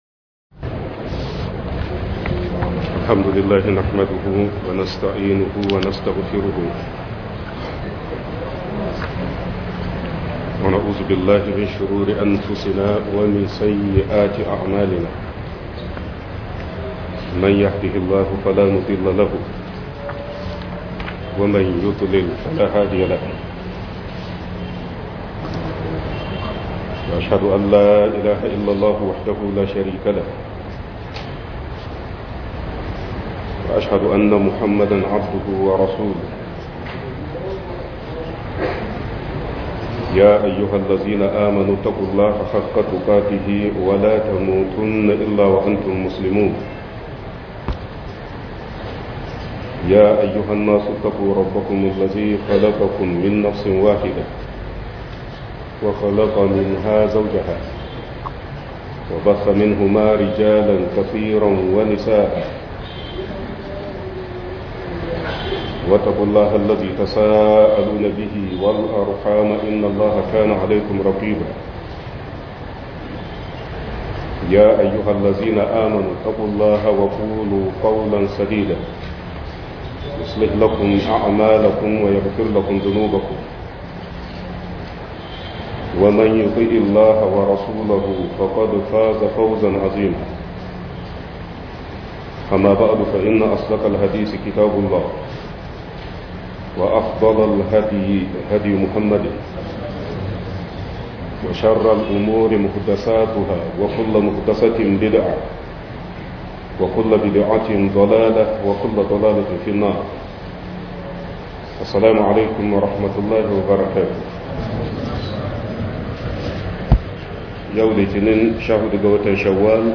Azumin Sittu Shawwal - MUHADARA